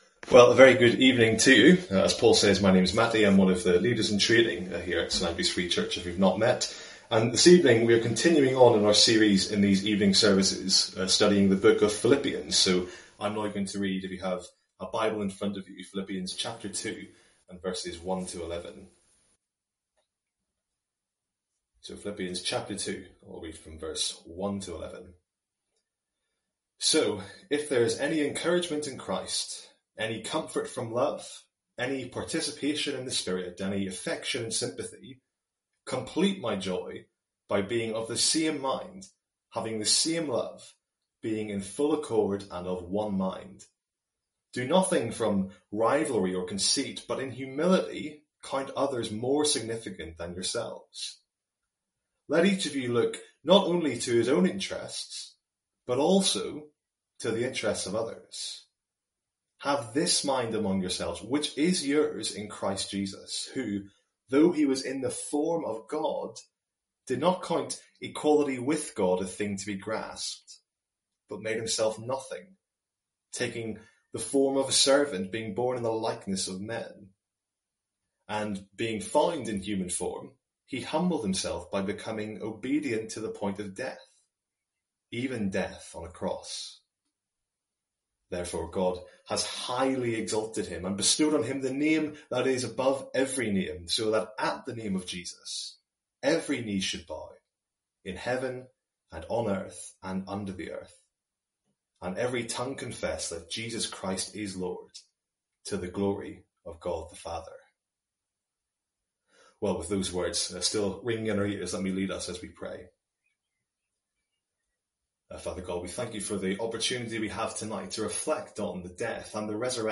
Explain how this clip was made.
From our evening series in Philippians.